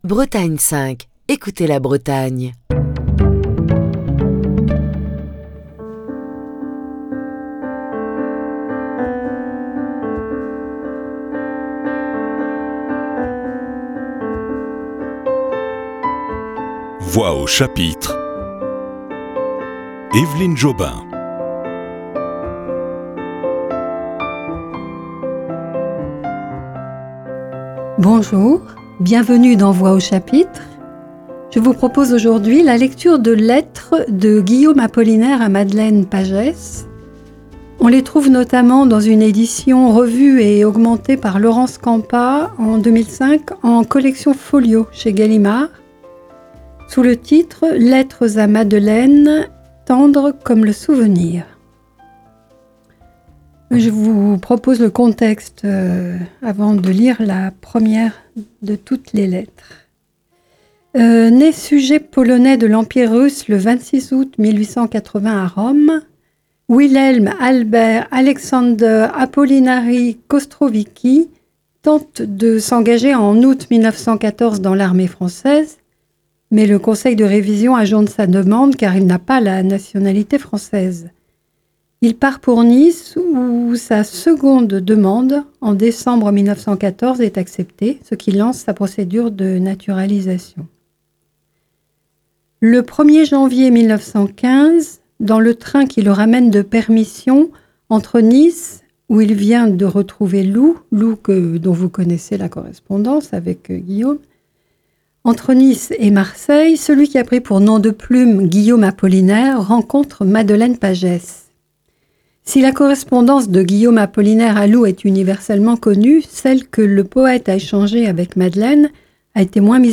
la lecture des lettres de Guillaume Apollinaire à Madeleine Pagès. Le 2 janvier 1915, Guillaume Apollinaire prend le train en gare de Nice.